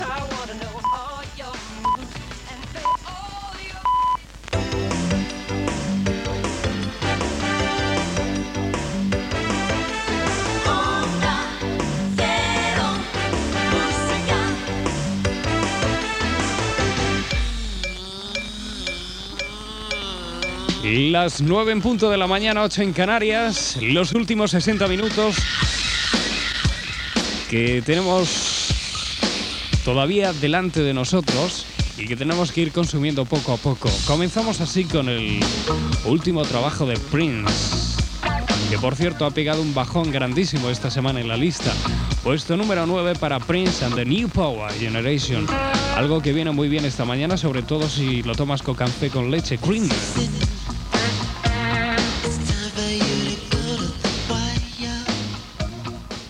Indicatiu, hora, tema musical